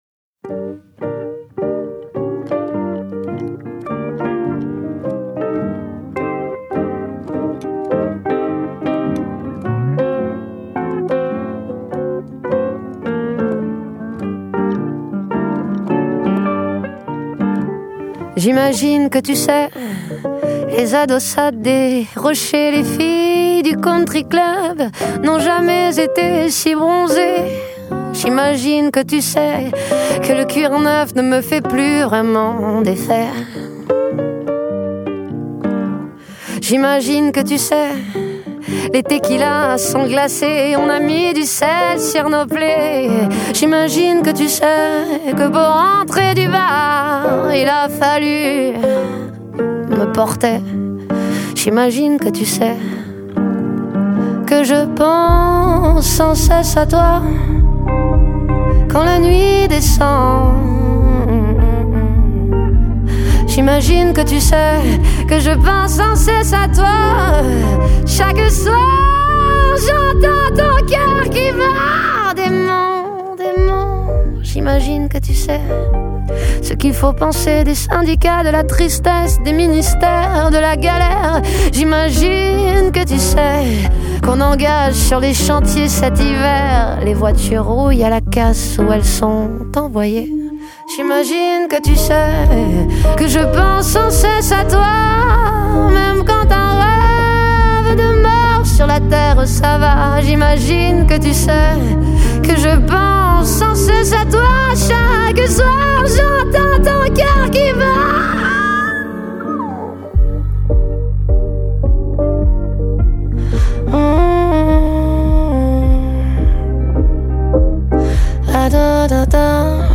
nastroje rodem z kabaretów